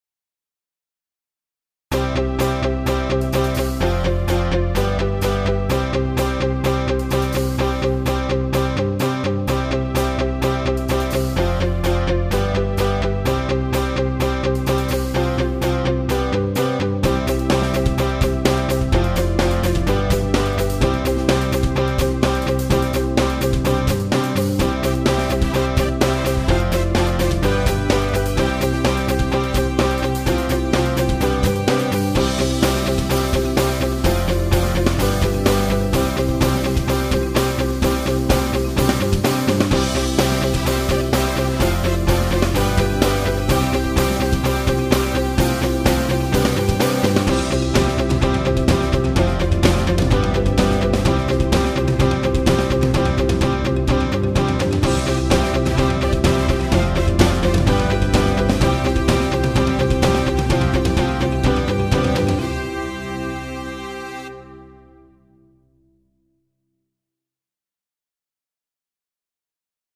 今回の楽器構成は、リード、ギター、ベース、ドラムス。この四つだけで作ってる。
最初にギターのリフ？　を閃いて、それに合わせて作っていったという感じだったかな。
今回の課題は、リフの繰り返しだけじゃなくて、そこから音楽を展開させていくことだった。
各楽器にＥＱを当てた。
ギターにＳｅｎｄでリバーブをかけた。